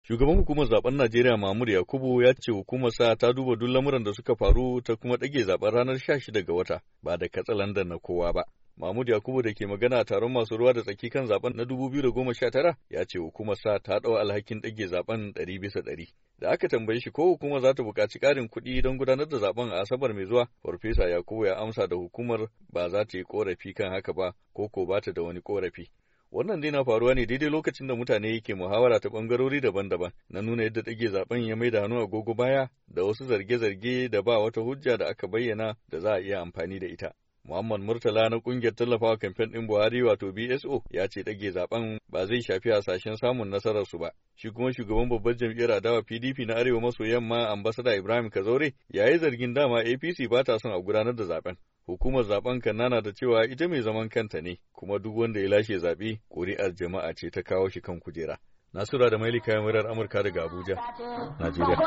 Yayin da yake magana wani taron manema labarai kan dage zaben na 2019, Prof. Yakubu, ya ce hukumarsa ta dau alhakin dage zaben 100 bisa 100